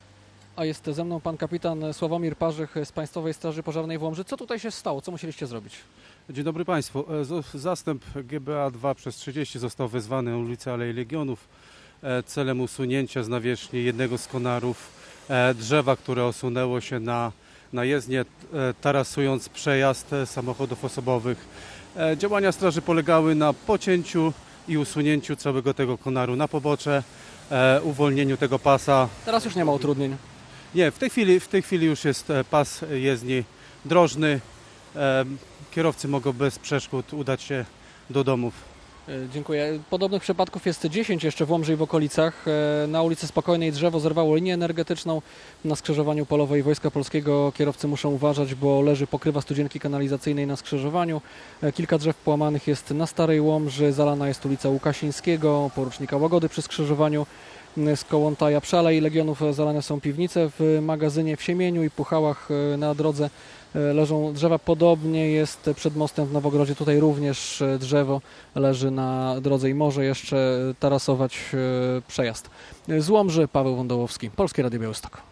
Łomża po ulewie - relacja